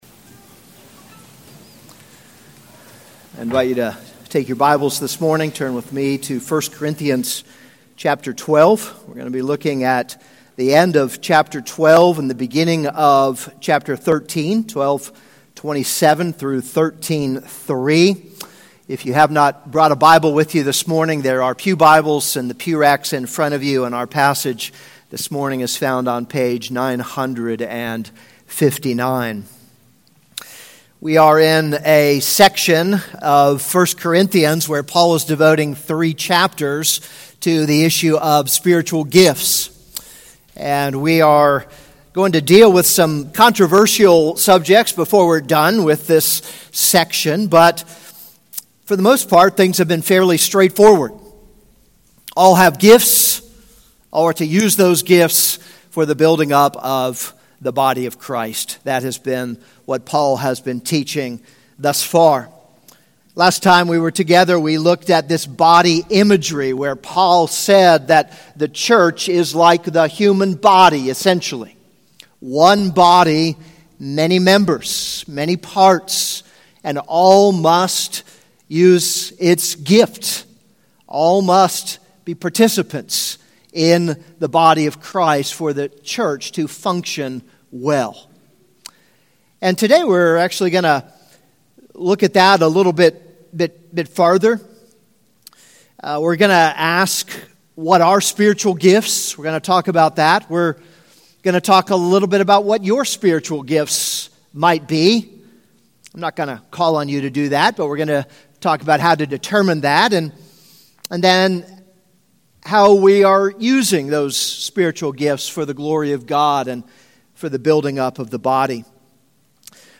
This is a sermon on 1 Corinthians 12:27-13:3.